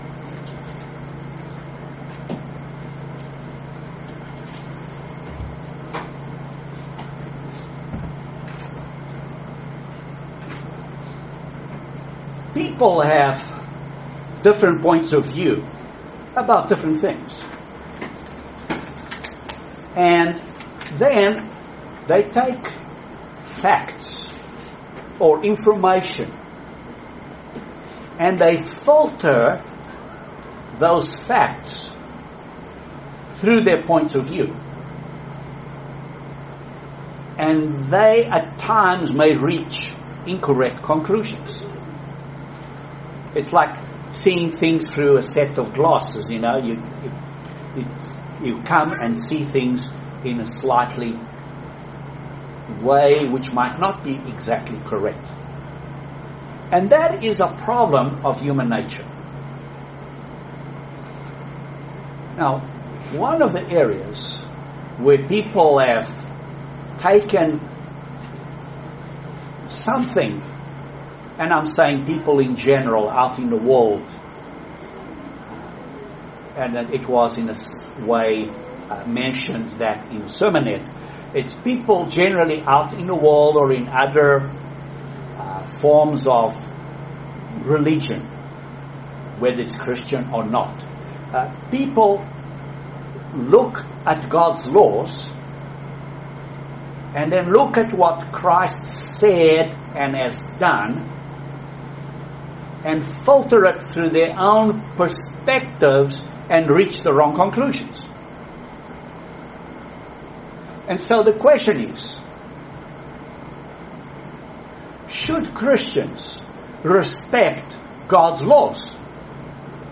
Great Sermon about the righteousness of God. Has God's view of His law ever changed? What is the righteousness of God?